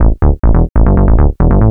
Techno / Bass / SNTHBASS141_TEKNO_140_A_SC2.wav